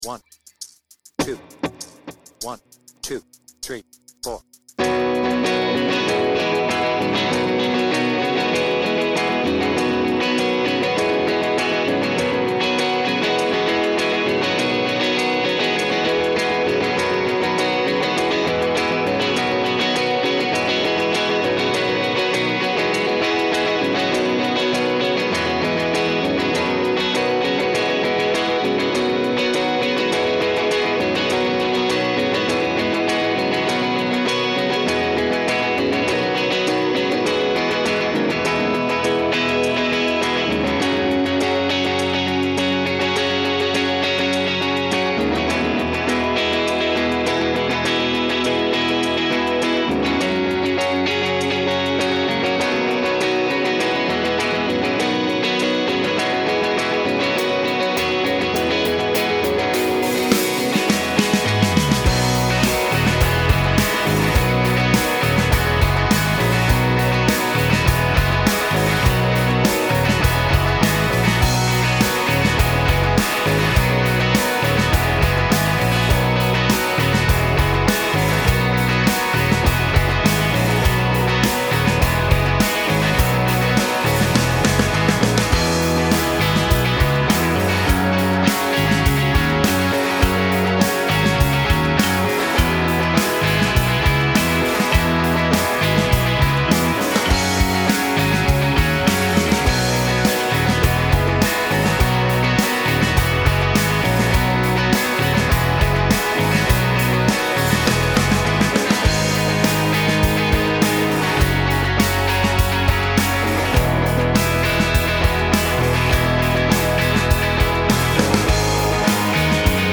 BPM : 83
Tuning : E
Without Vocals